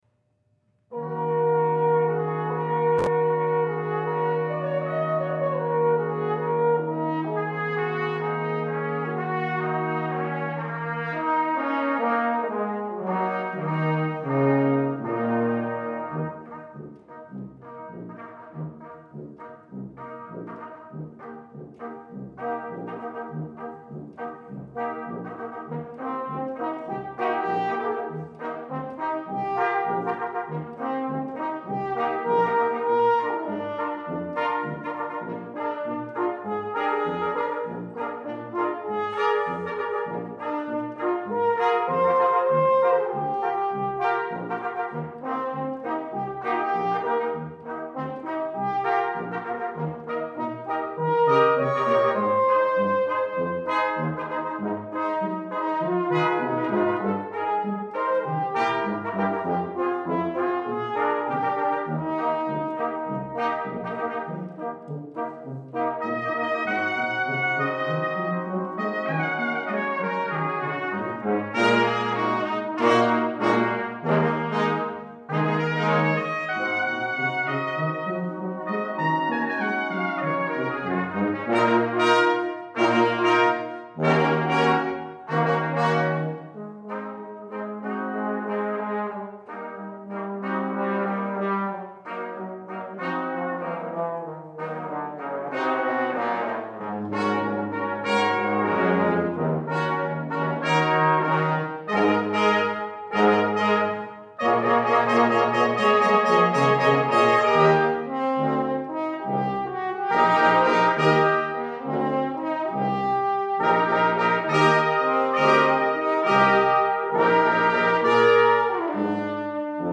for Brass Quintet (2005)
Mournful, blues sonorities